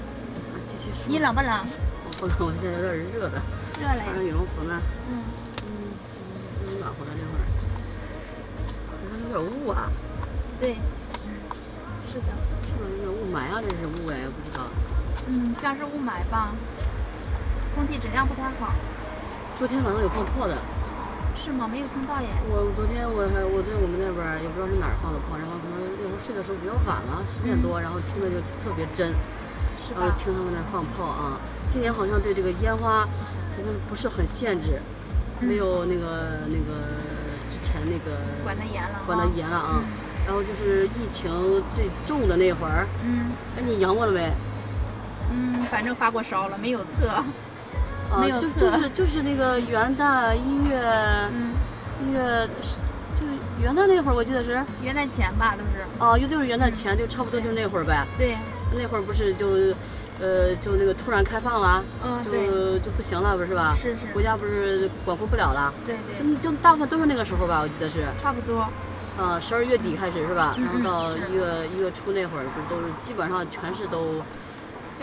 • 超百人的车内驾驶员和乘客间语音对话数据，覆盖不同驾驶场景；
本次赛事发布一个真实的车内多通道语音语料库，包含约1000 **+**小时、车内真实录制的多通道普通话语音数据，收音设备包含车内分布式麦克风收集的远场数据，以及参与者的头戴麦克风收集的近场数据。